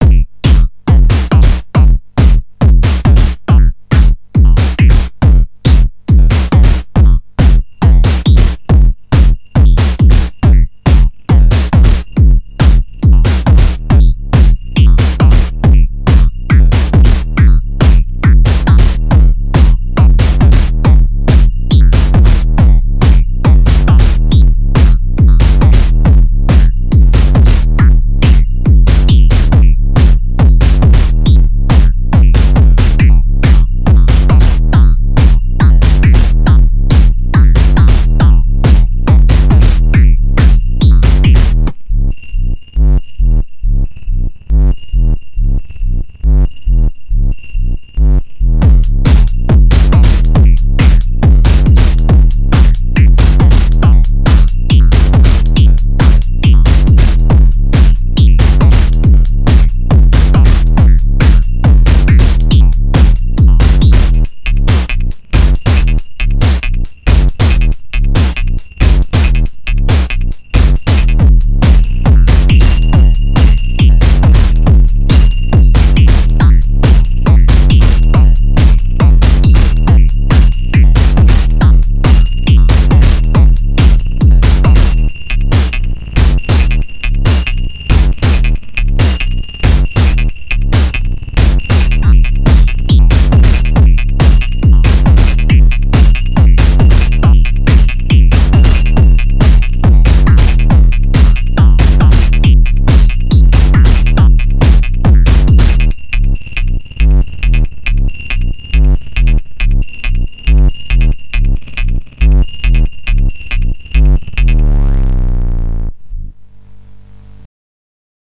LXR-02 into Alesis 3630.